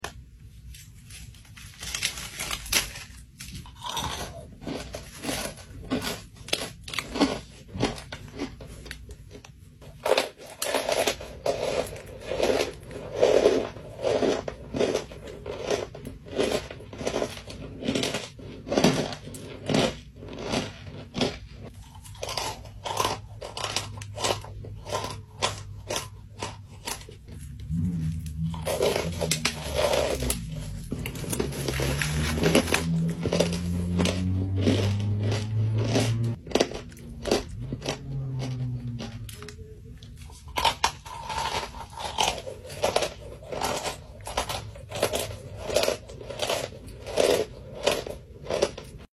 Pressed Brick Crunches !!